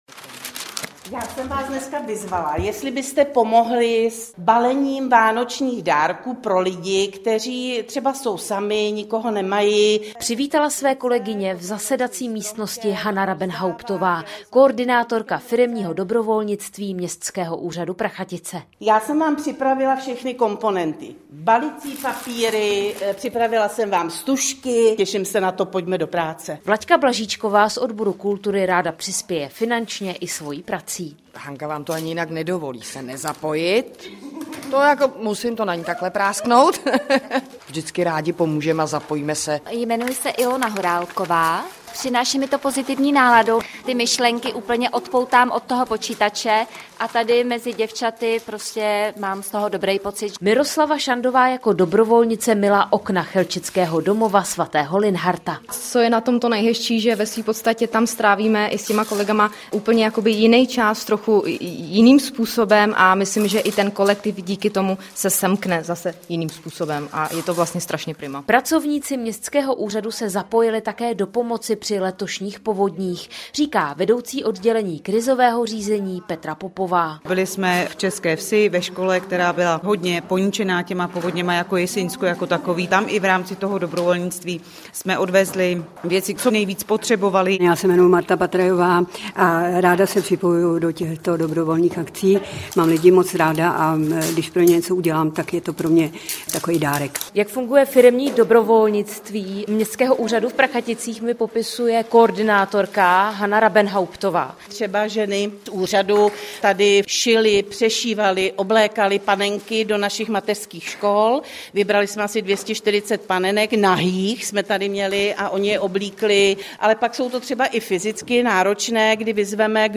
Reportáže z jižních Čech, písničky na přání a dechovka.